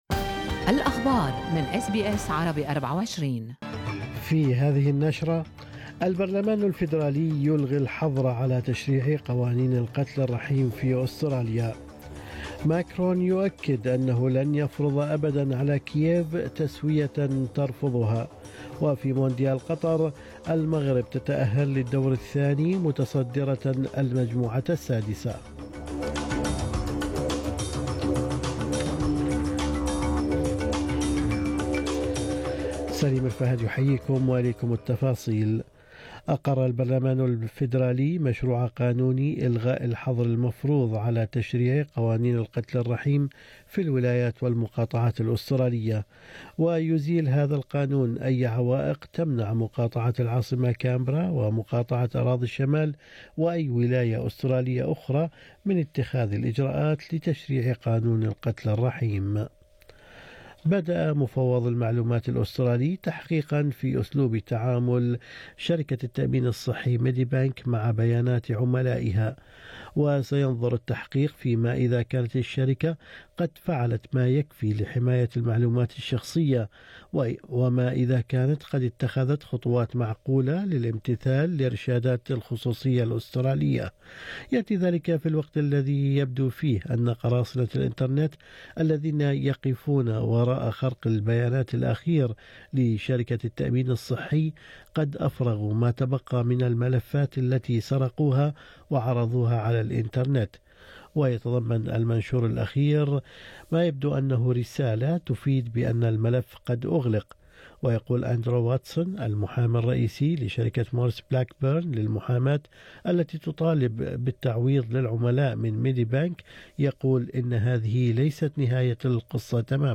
نشرة أخبار الصباح 2/12/2022